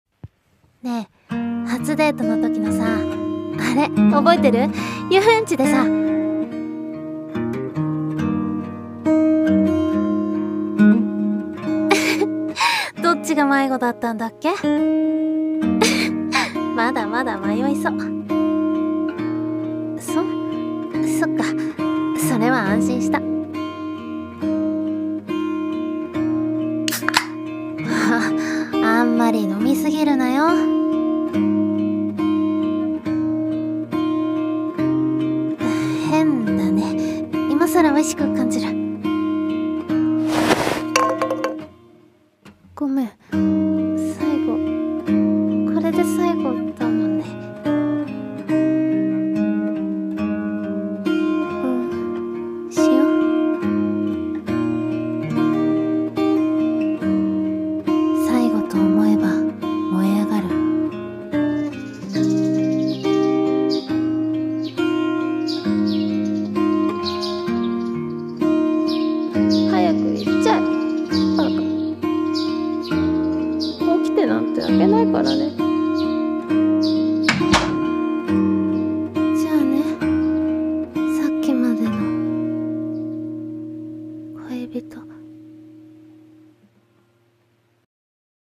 【声劇】またね、って言わない